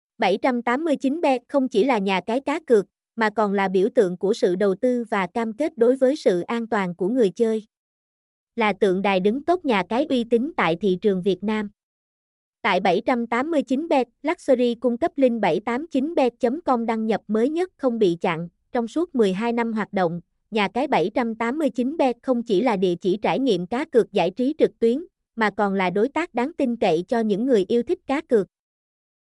mp3-output-ttsfreedotcom.mp3